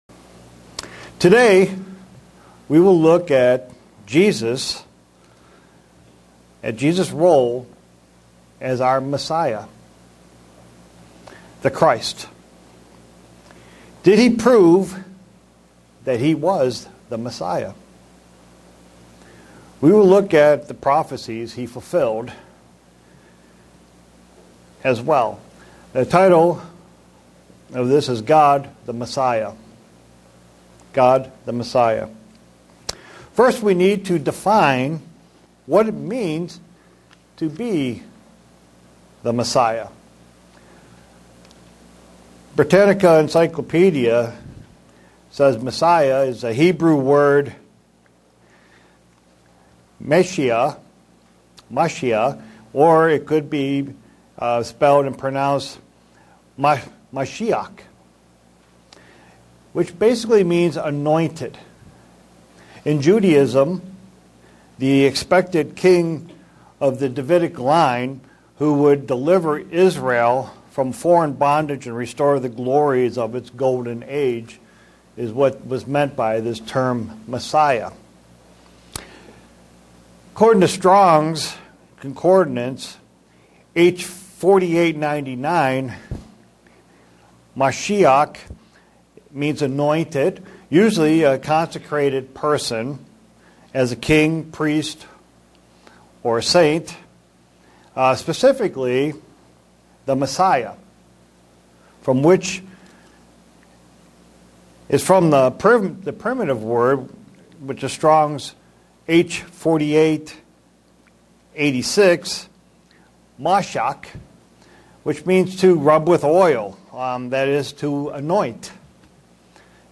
Given in Buffalo, NY
Print The title Messiah in the Bible how it applies to Christ. sermon Studying the bible?